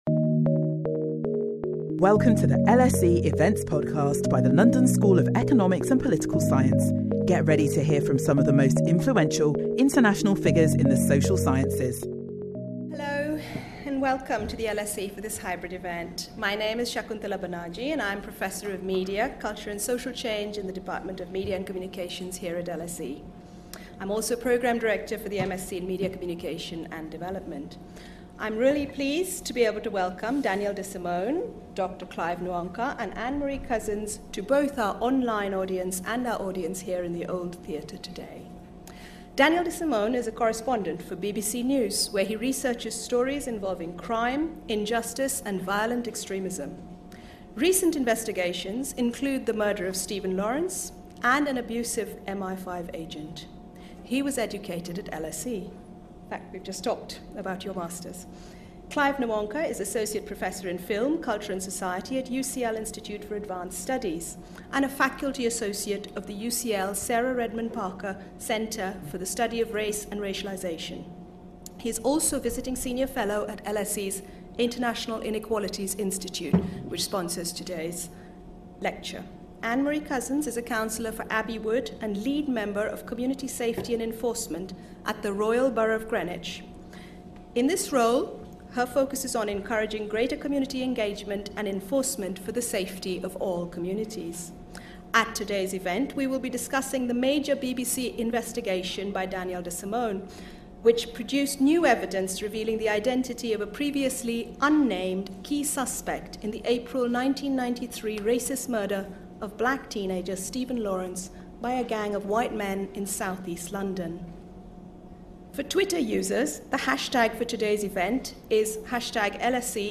The panel explore the potential of contemporary investigative journalism practices in uncovering historical institutional failings and intervening in structural racial inequalities.